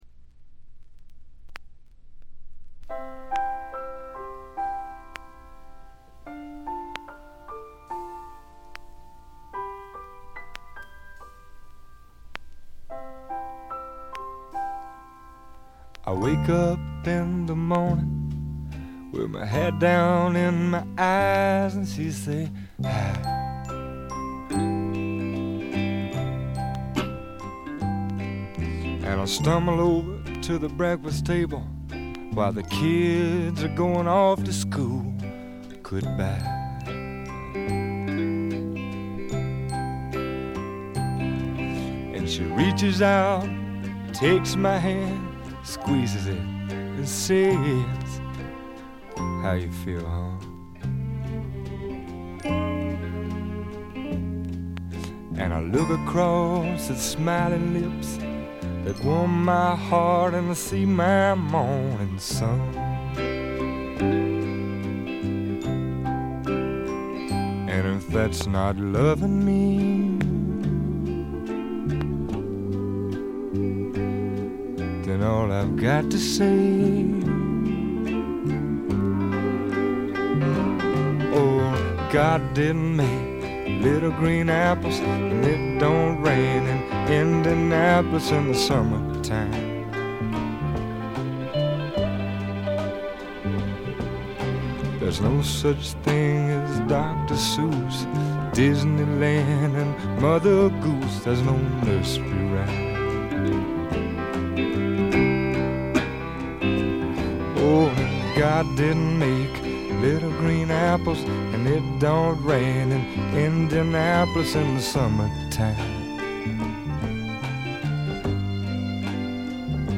チリプチ、プツ音多め大きめ。
スワンプ基本！
試聴曲は現品からの取り込み音源です。
Guitar, Harmonica